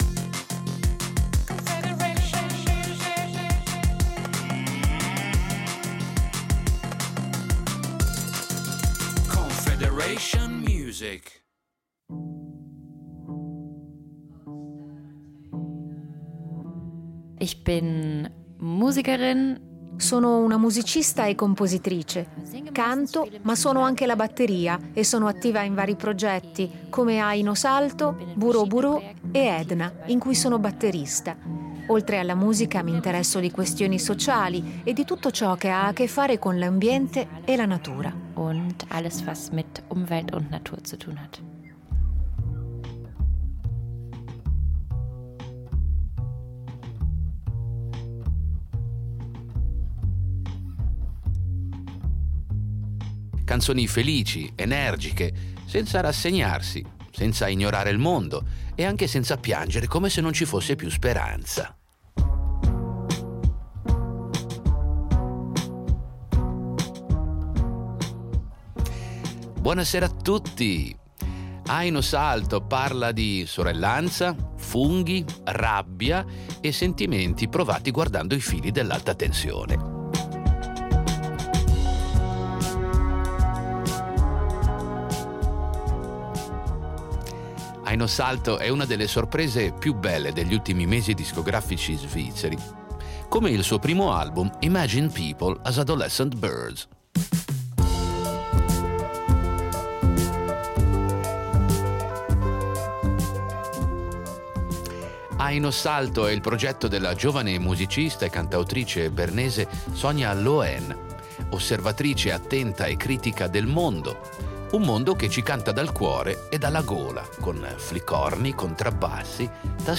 Musica pop
Canzoni felici ed energiche cantate senza rassegnazione e senza ignorare il mondo, ma anche senza piangere come se non ci fosse più speranza.